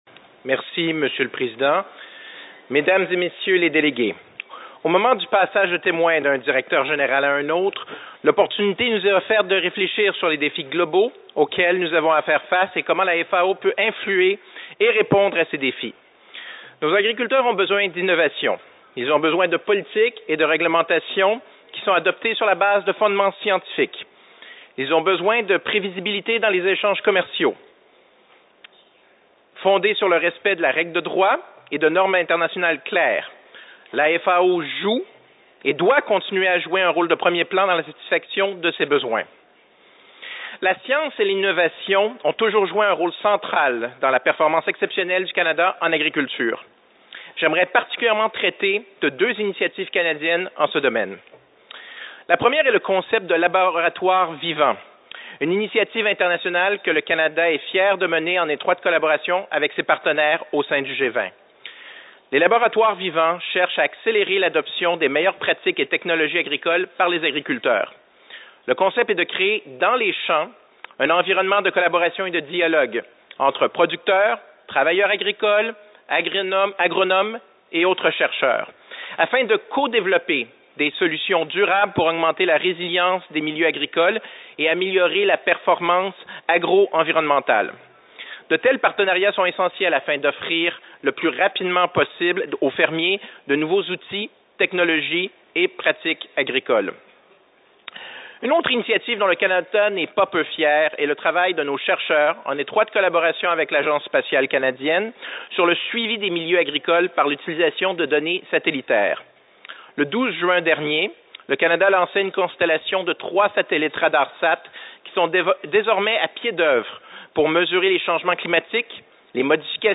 Addresses and Statements
His Excellency Frédéric Seppey Assistant Deputy Minister, Market and Industry Services Branch, Agriculture and Agri-Food of Canada